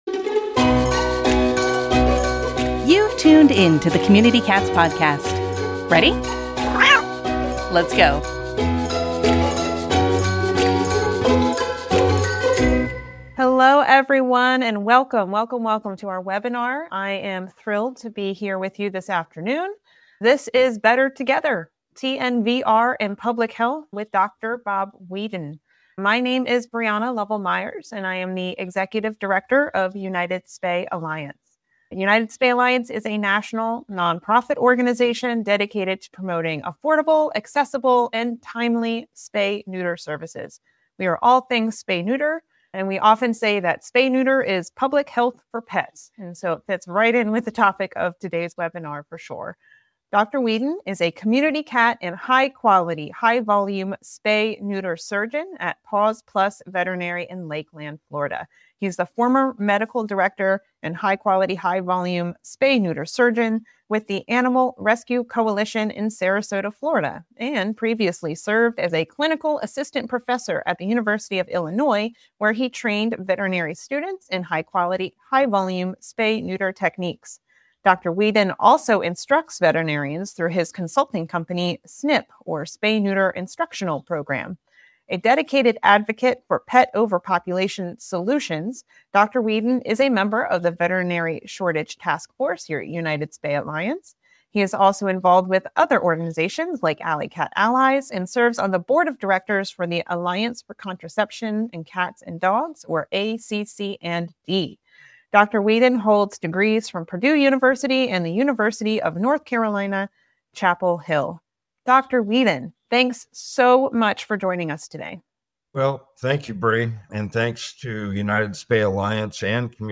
This conversation is more than an academic discussion; it’s a rallying cry for more integrated, humane, and scientifically-backed approaches to community cat care.